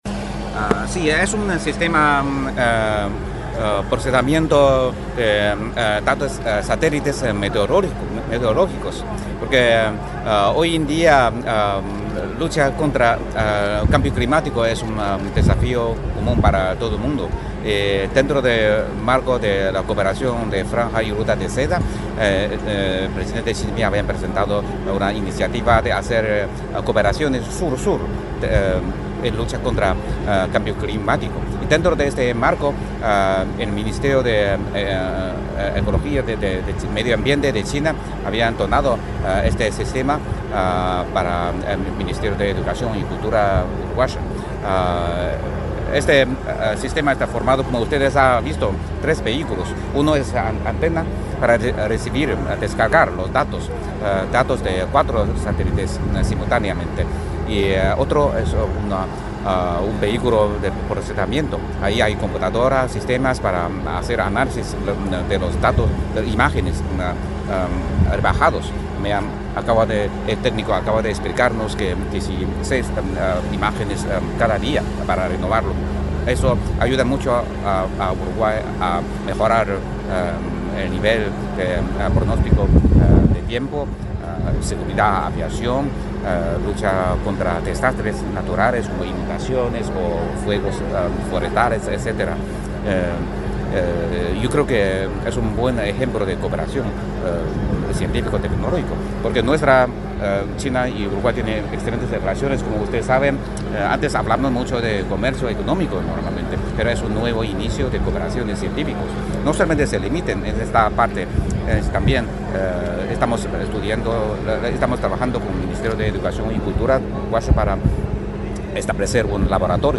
Declaraciones del embajador de la República Popular China en Uruguay, Huang Yazhong
El embajador de la República Popular China en Uruguay, Huang Yazhong, dialogó con la prensa, luego de participar en la presentación de un sistema